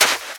STEPS Sand, Run 17.wav